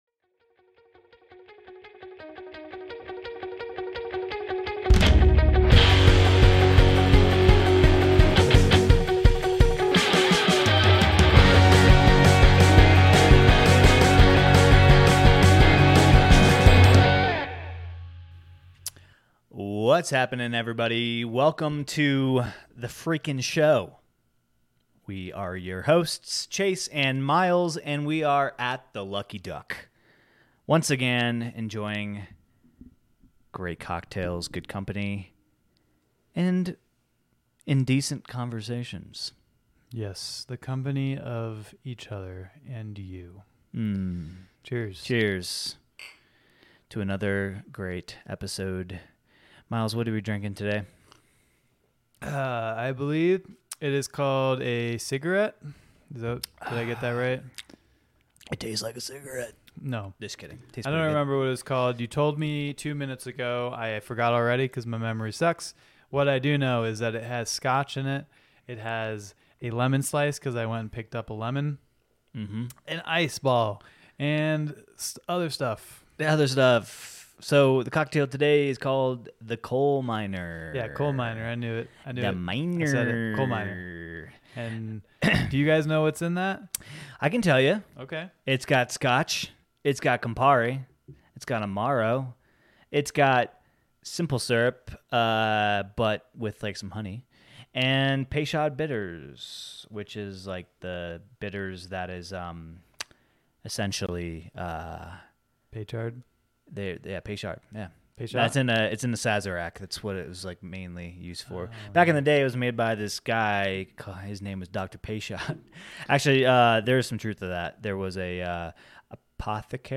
share conversation over cocktails.